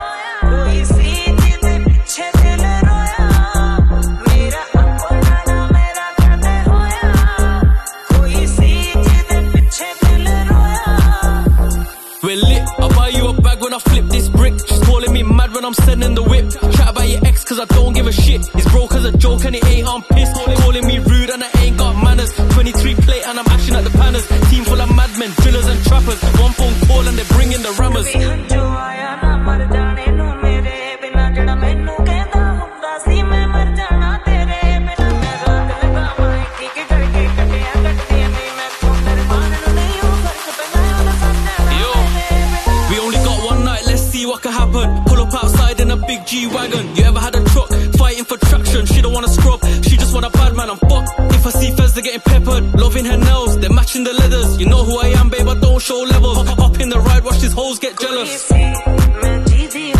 F1 light completed on this sound effects free download